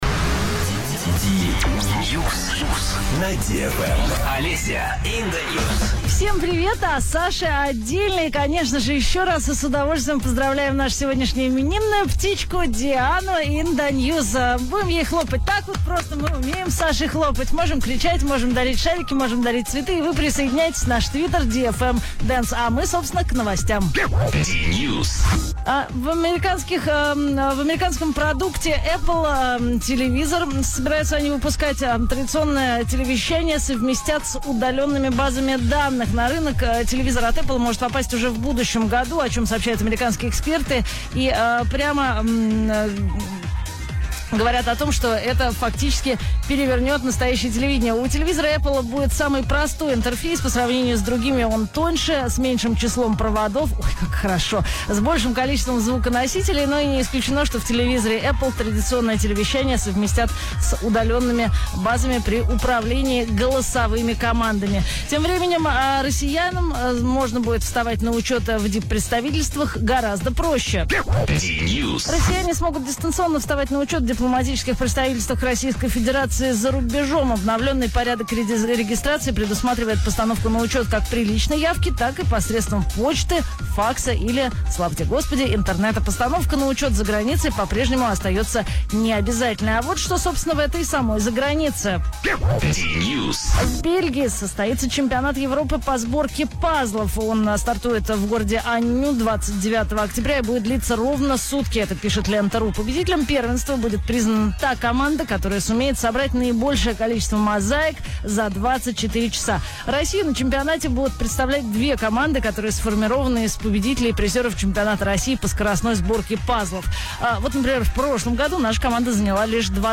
Ведущий новостей на DFM - практически диджей! Только еще более разговорчивый на несколько тем за пару минут.
| Теги: развлекательные новости, дневной эфир, диджеи, диджей, рубрика, радиоведущий, специальная программа, DNews, эфир, DFM